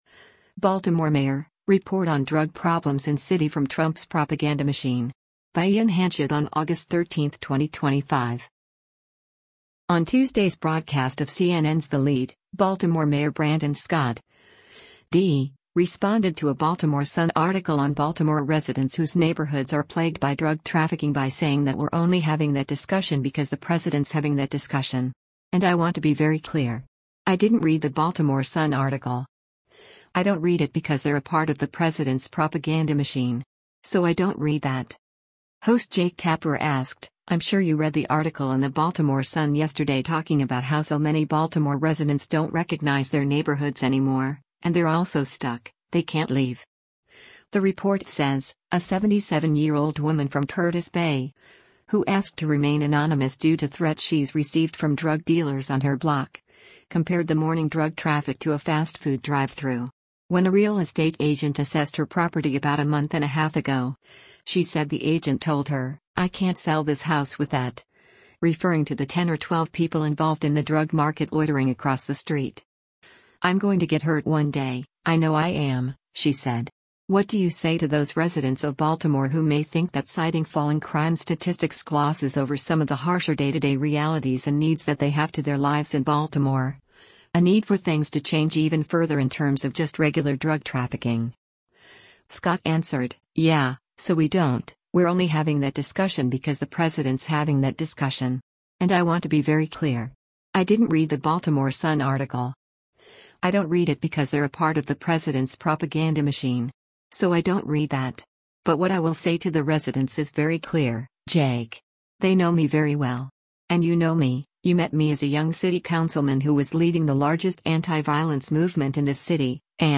On Tuesday’s broadcast of CNN’s “The Lead,” Baltimore Mayor Brandon Scott (D) responded to a Baltimore Sun article on Baltimore residents whose neighborhoods are plagued by drug trafficking by saying that “we’re only having that discussion because the president’s having that discussion.